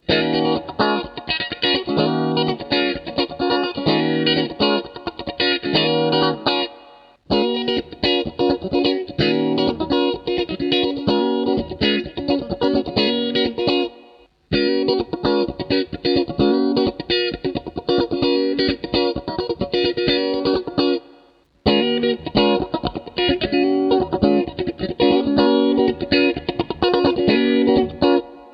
Tele στο tweed με την 12ΑΥ7 και ολιγη * απο το νεο phaser (οπως λεμε νεο ΛΟΤΤΟ) *= χαμηλωμενο depth Tele_phYnk.wav Attachments Tele_phYnk.wav Tele_phYnk.wav 2.4 MB